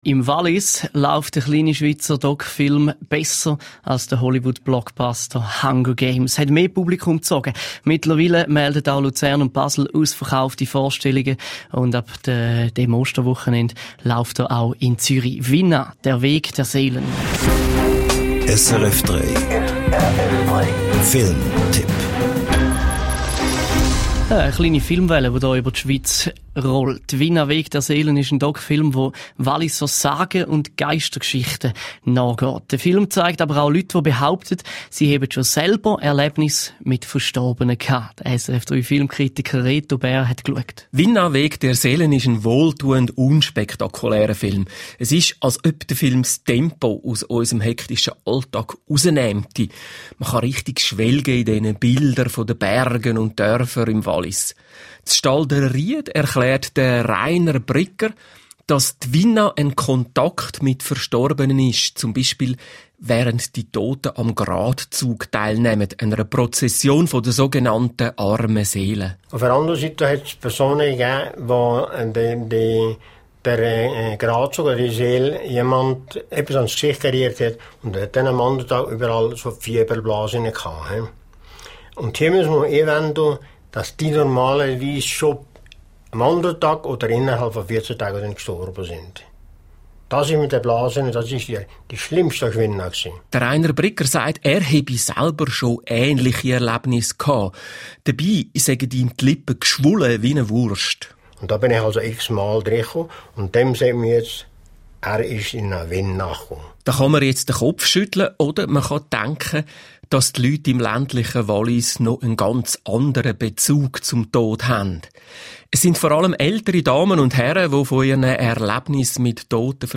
Radiobericht SRF 3 - mp3